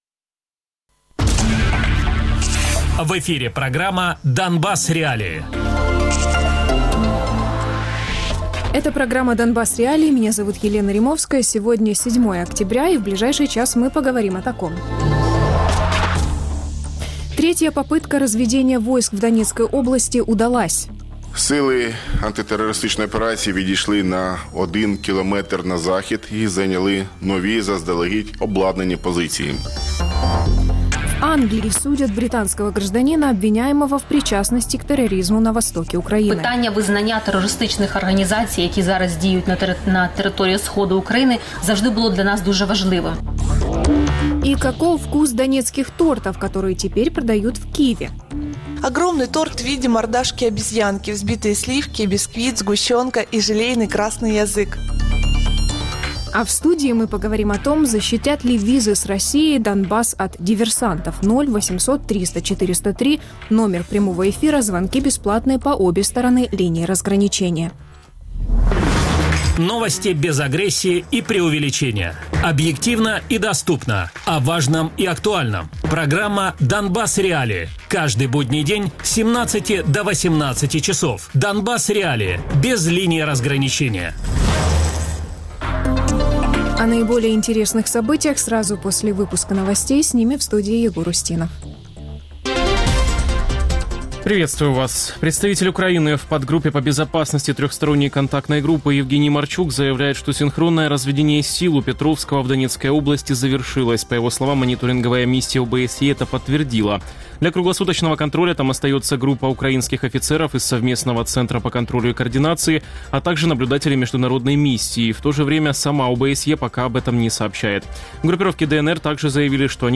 политолог-международник (студия); Анна Гопко - глава комитета ВР по иностранным делам (по телефону) Радіопрограма «Донбас.Реалії»